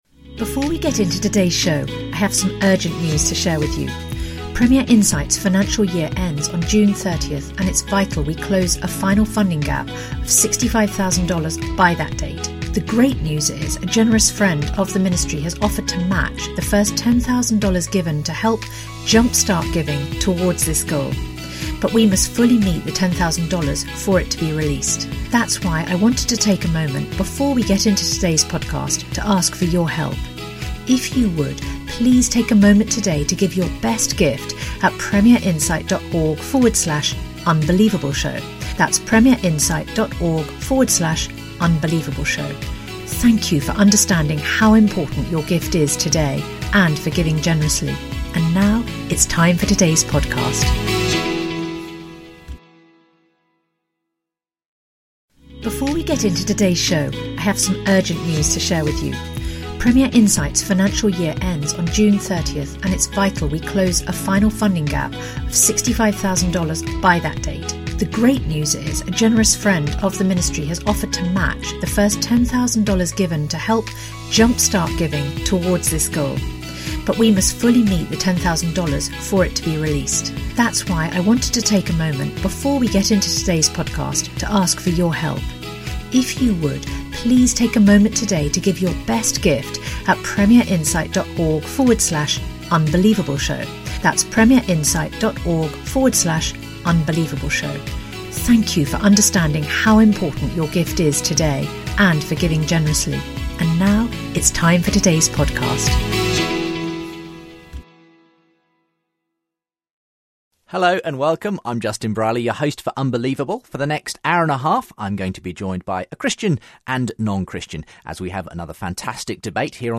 Christianity, Religion & Spirituality 4.6 • 2.3K Ratings 🗓 21 November 2014 ⏱ 81 minutes 🔗 Recording